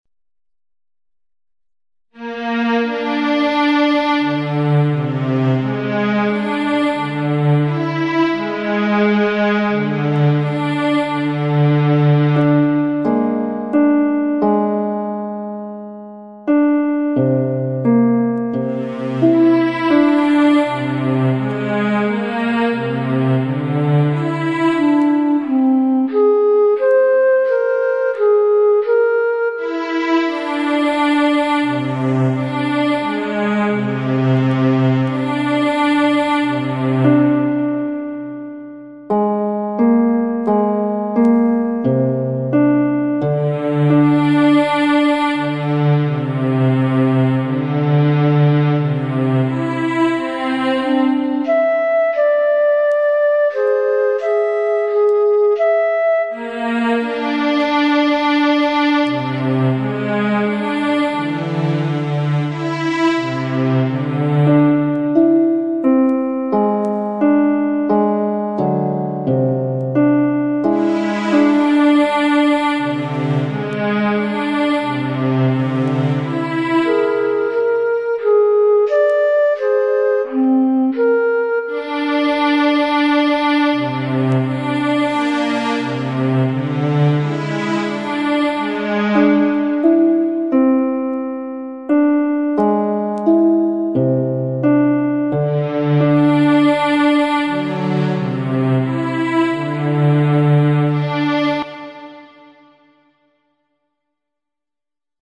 The calcium binding sites are played by harp, the flanking alpha helical regions are played by a string ensemble and the introductory and linking regions are played by flute.